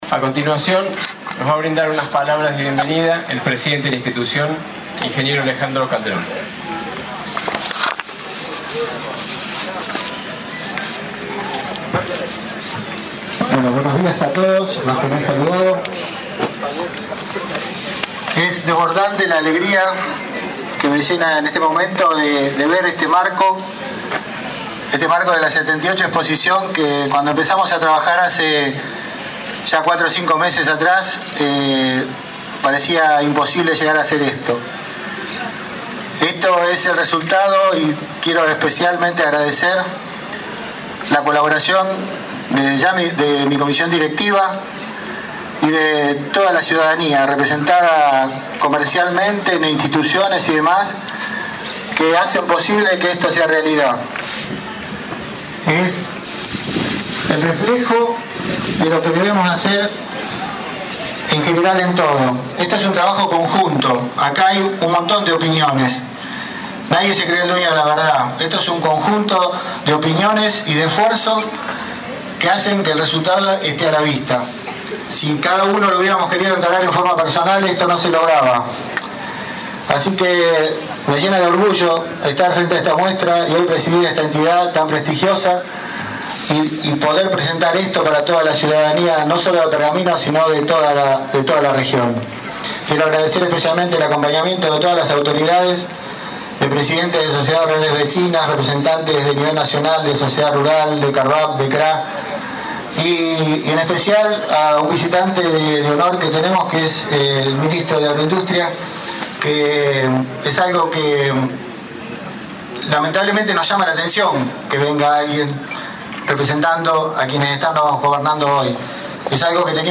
Ayer 10 de septiembre se realizó la apertura oficial en el patio principal de la expo.